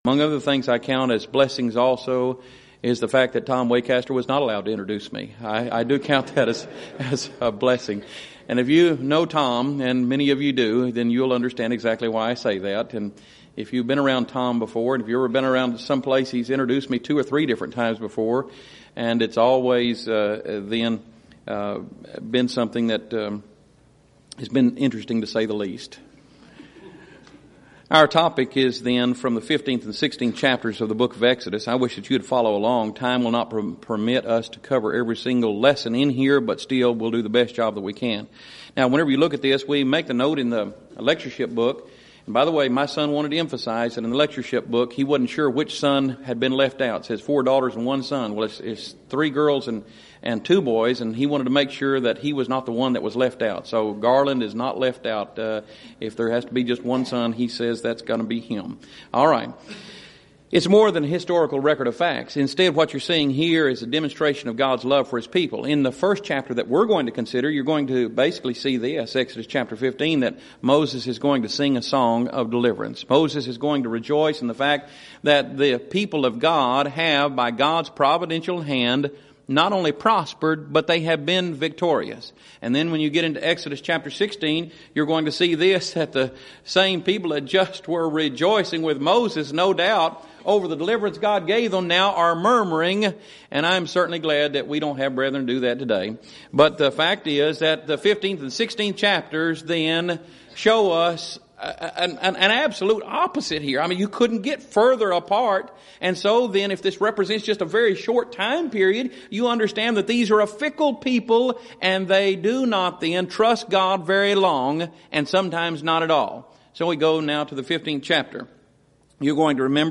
Series: Schertz Lectureship Event: 2nd Annual Schertz Lectures Theme/Title: Studies In Exodus